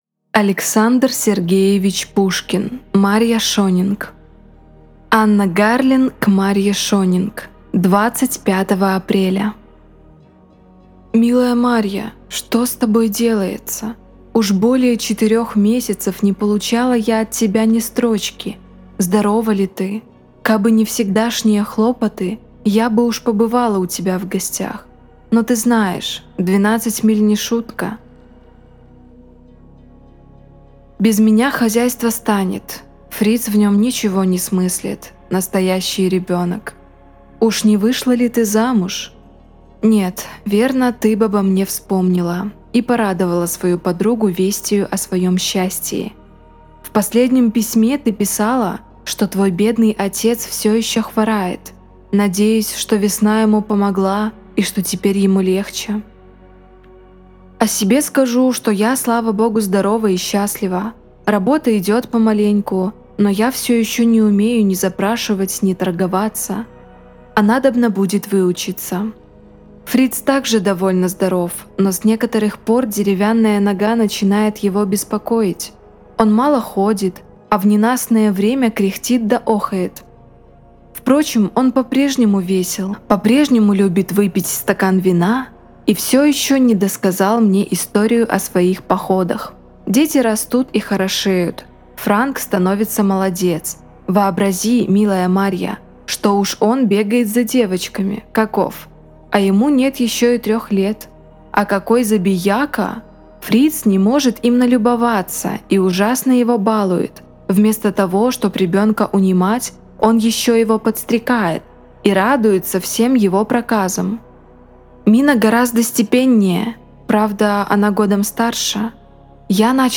Аудиокнига Марья Шонинг | Библиотека аудиокниг